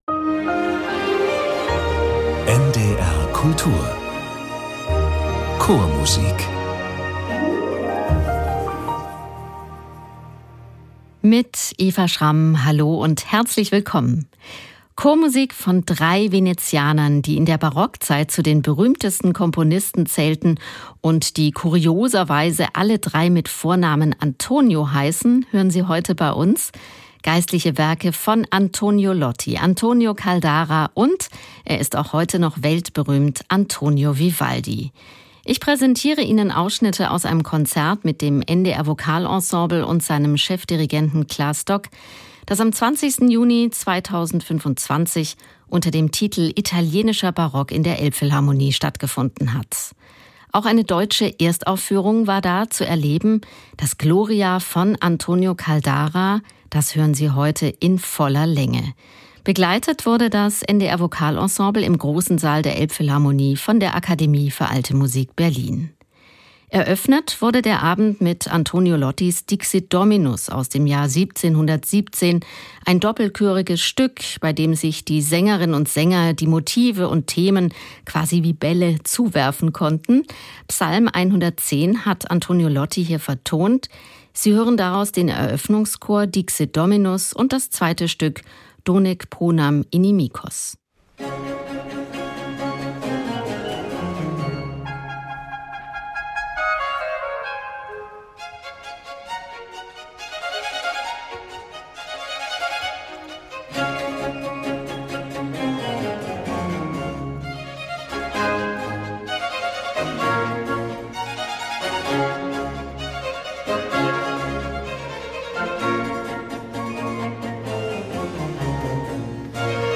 Italienischer Barock in der Elbphilharmonie ~ Chormusik - Klangwelten der Vokalmusik entdecken Podcast
Das NDR Vokalensemble und die Akademie für Alte Musik Berlin mit Werken von Vivaldi, Lotti und Caldara.